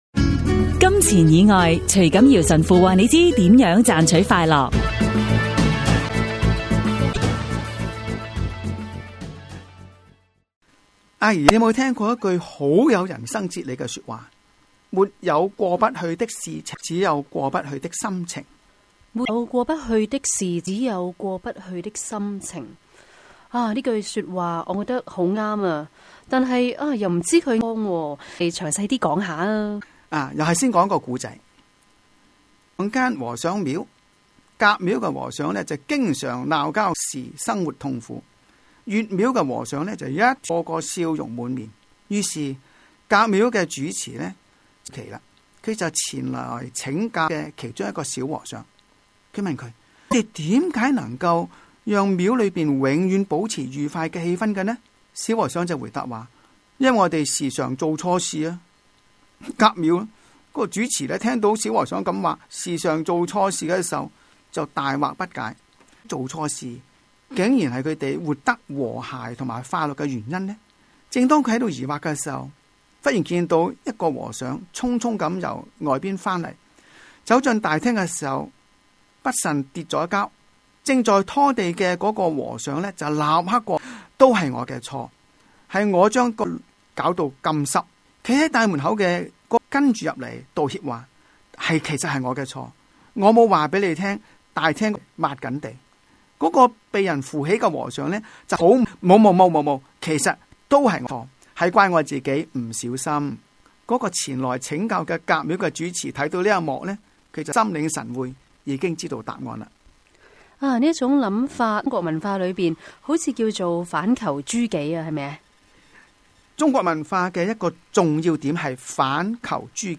自2007年底，我們在新城財經台推出「金錢以外」電台廣播節目，邀請不同講者及團體每晚以五分鐘和我們分享金錢以外能令心靈快樂、生命富足的生活智慧，讓聽眾感悟天主的美善和睿智。